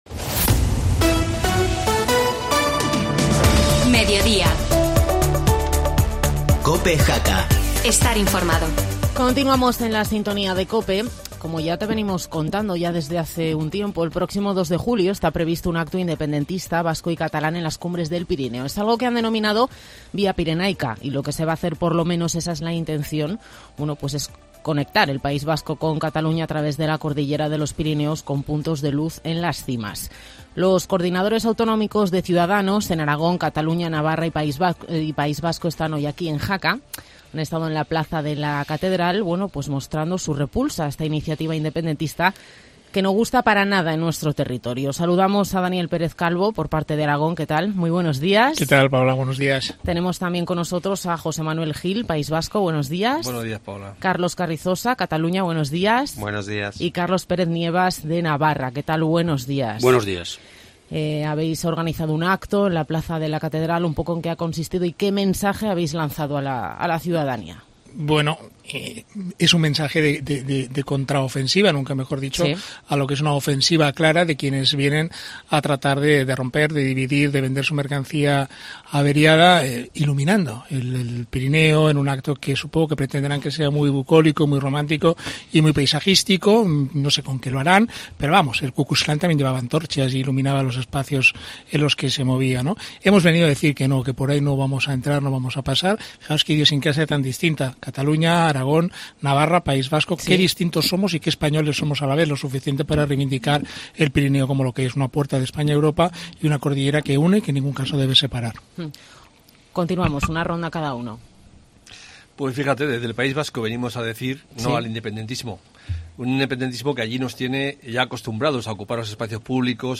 Entrevista a los líderes de Cs en Aragón, Cataluña, Navarra y País Vasco